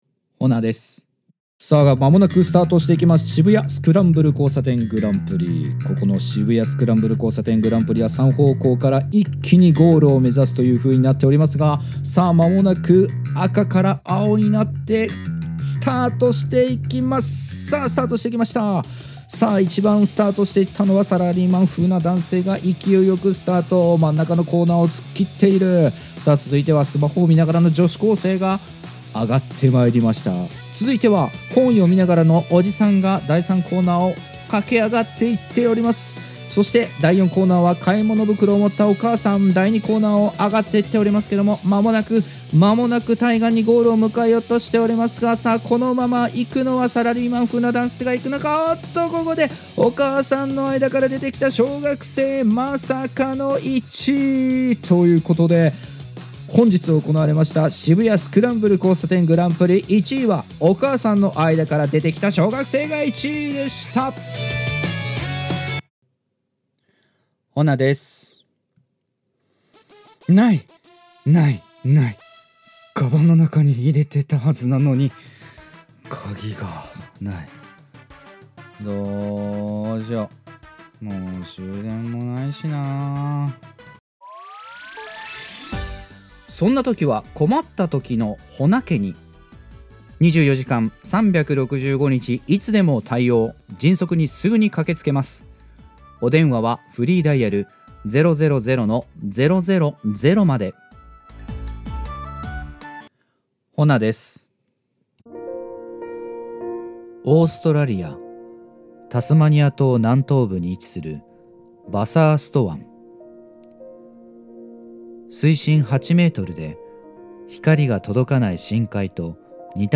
ナチュラルな、あたたかボイス